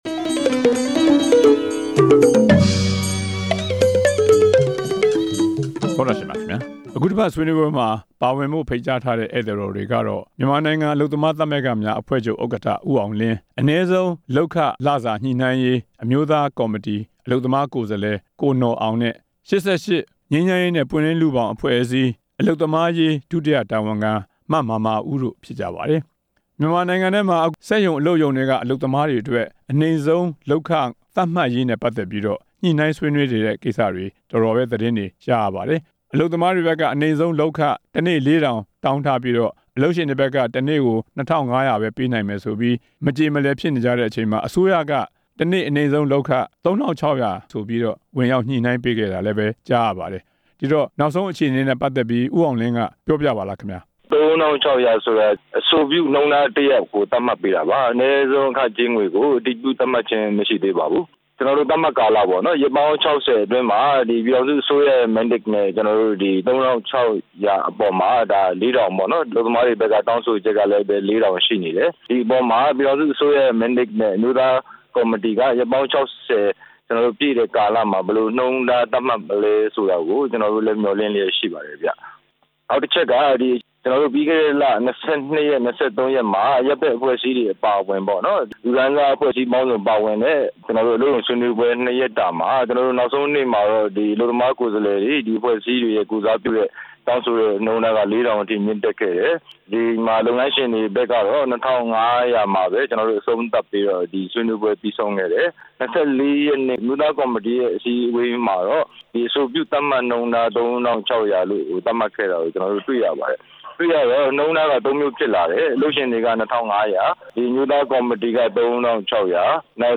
အနိမ့်ဆုံးလုပ်ခသတ်မှတ်ရေး ပြေလည်မှုမရသေးတဲ့အကြောင်း ဆွေးနွေးချက်